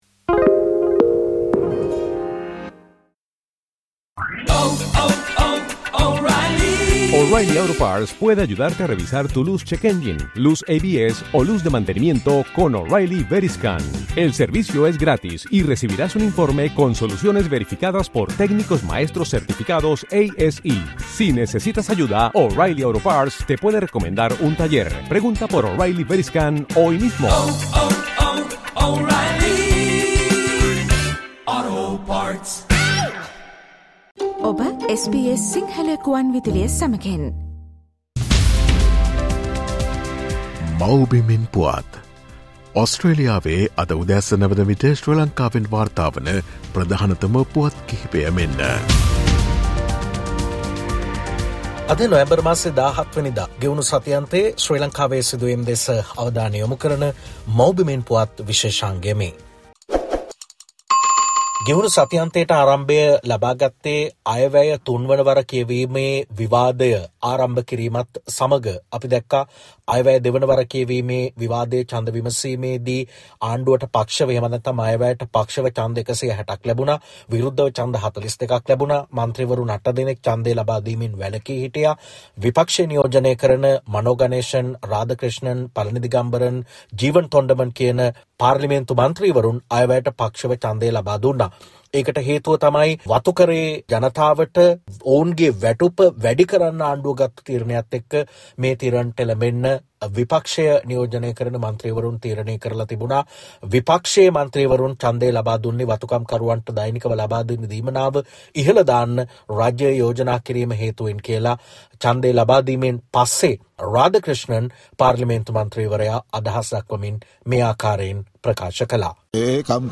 ගෙවුණු සතිඅන්තයේ ශ්‍රී ලංකාවෙන් වාර්තා වූ උණුසුම් හා වැදගත් පුවත් සම්පිණ්ඩනය.